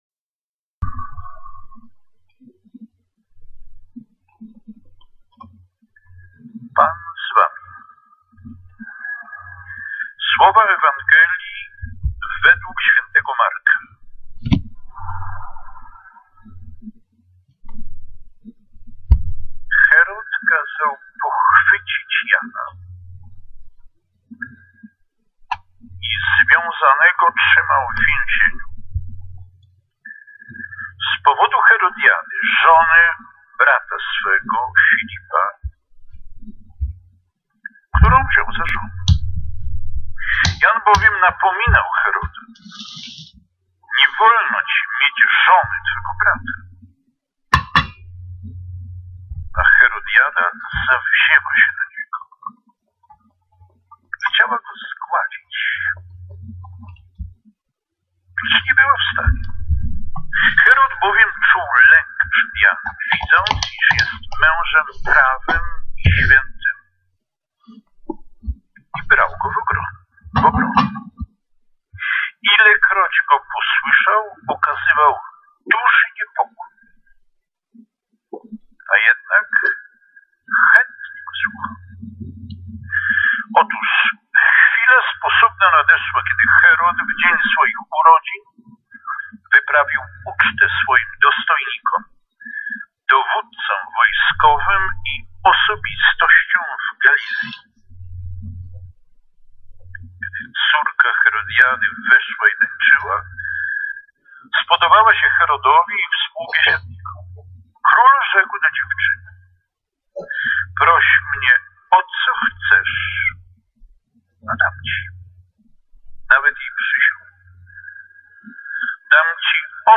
W dniach 29 i 30 sierpnia 2020 roku spotkali�my si� w Sanktuarium �w. J�zefa w Poznaniu, u oo. kamelit�w bosych.